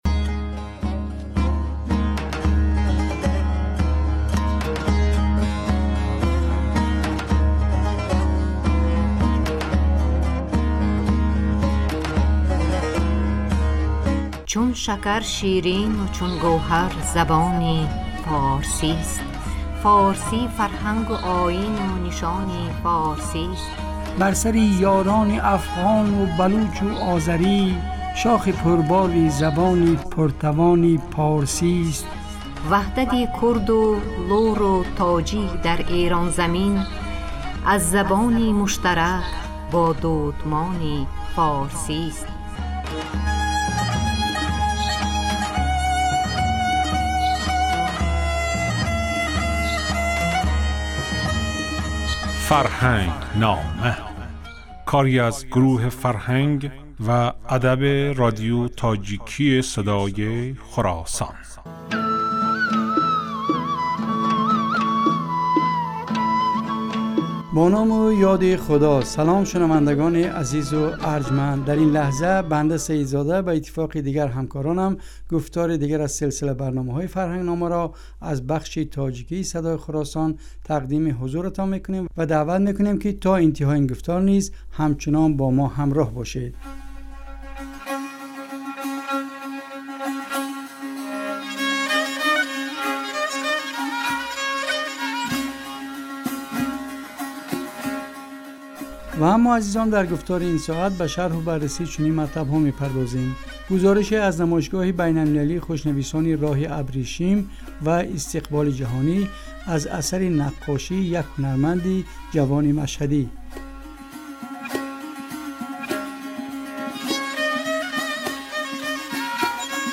Ин гуфтор ҳар ҳафта рӯзи сешанбе, дар бахши субҳгоҳӣ ва шомгоҳӣ аз Садои Хуросон пахш мегардад.